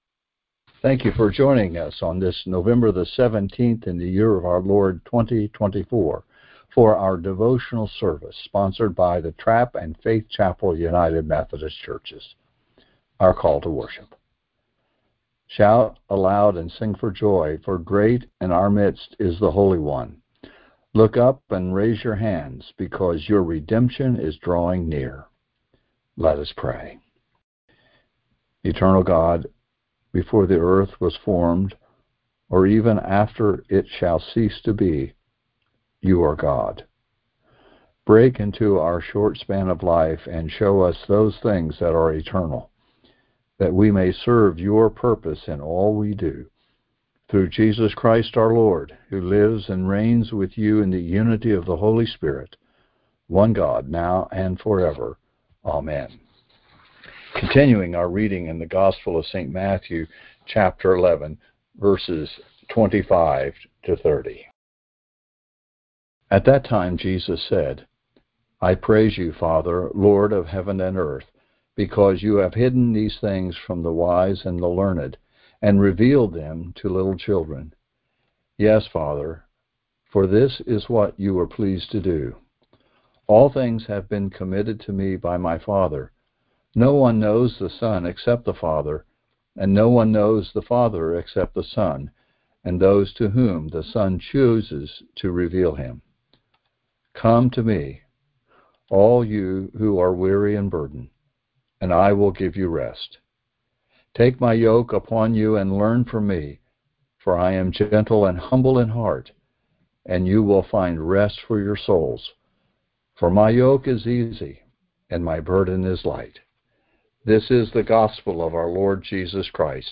Click here to listen to an audio recording of the most recent Charge-wide Conference Call Devotional Service